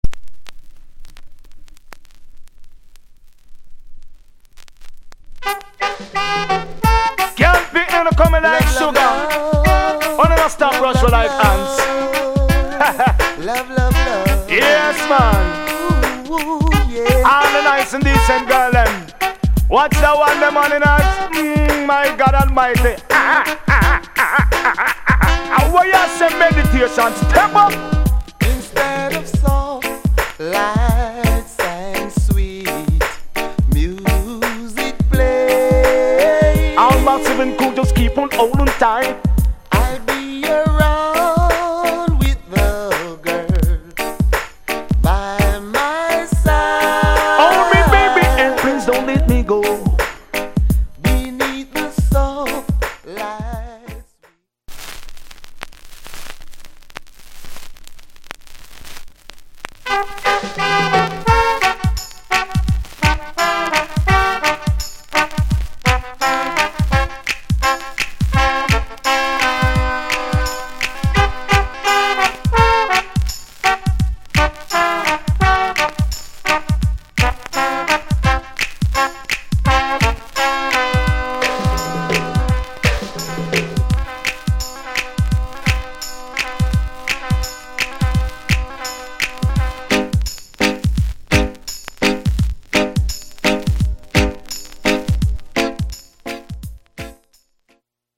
Cover + Dee Jay